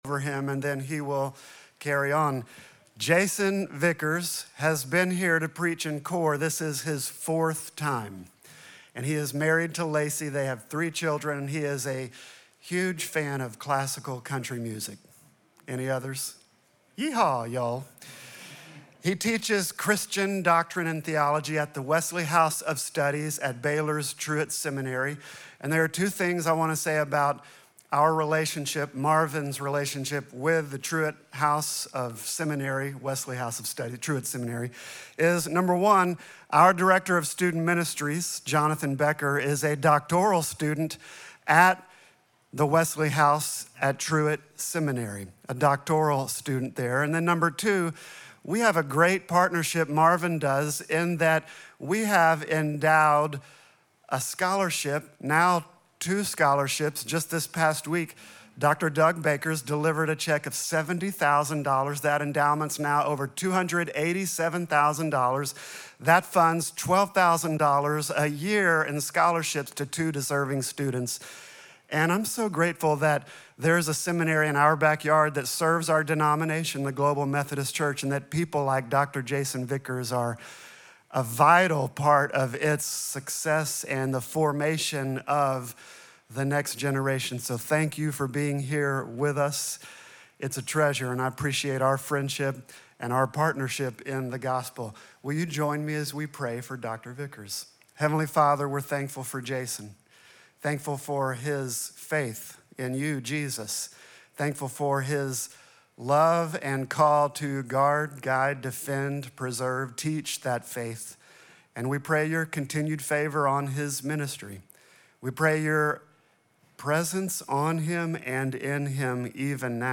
Sermon text: Ephesians 4:1-16